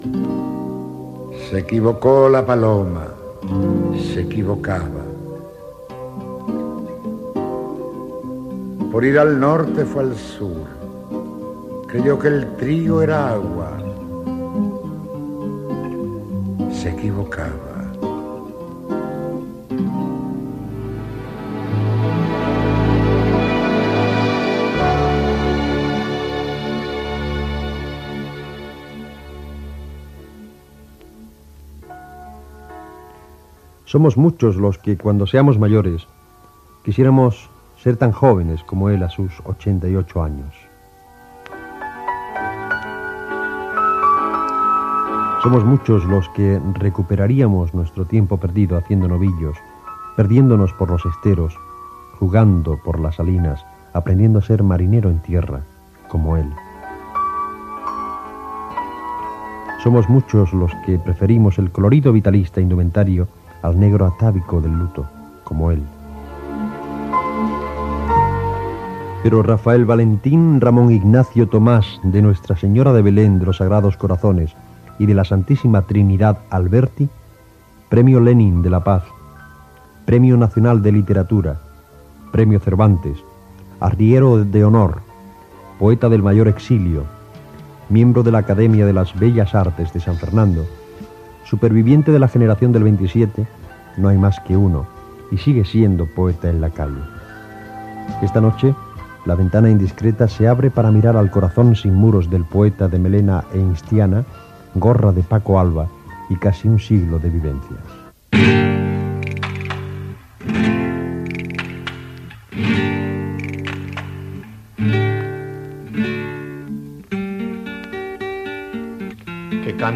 Recitat d'una poesia, presentació, cançó i entrevista al poeta Rafael Alberti